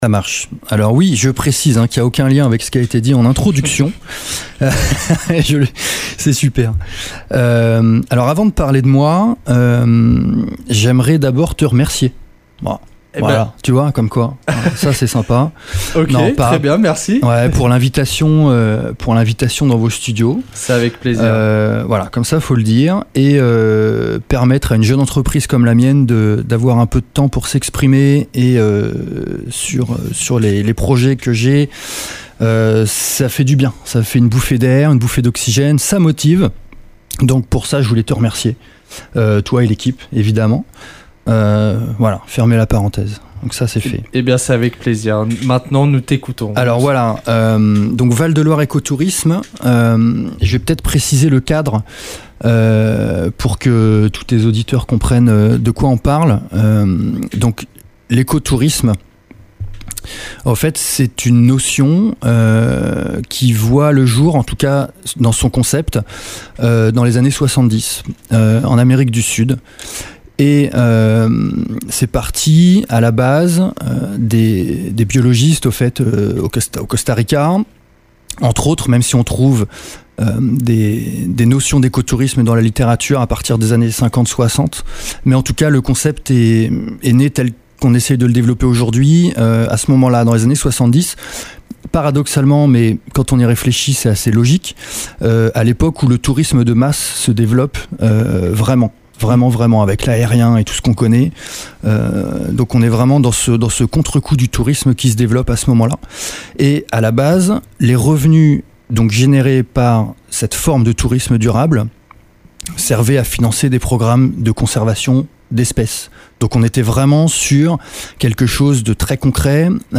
L’occasion d’évoquer le sujet de l’écotourisme et de de faire le point sur cette pratique de plus en plus répandue. Interview !